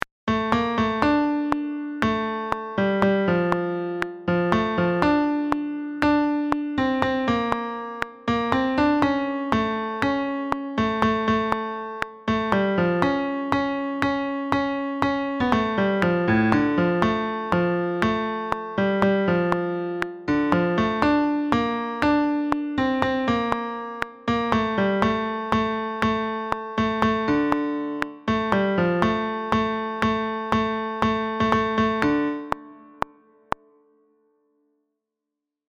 tenors-mp3 15 mai 2022